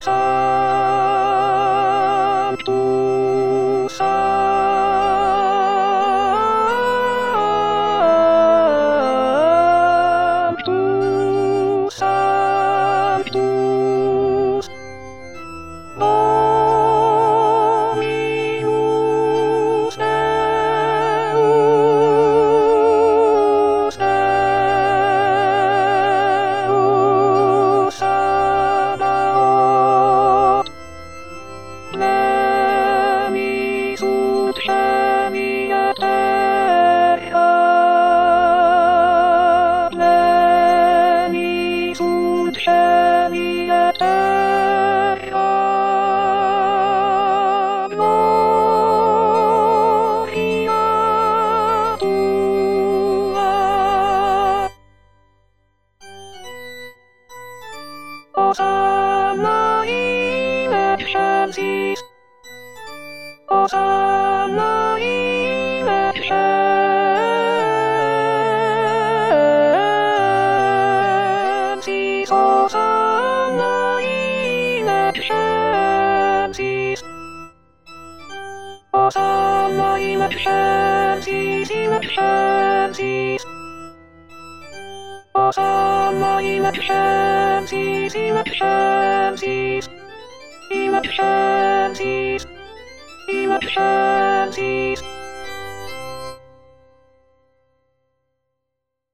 Les aides traditionnelles avec voix de synthèse
Sanctus-Alto.mp3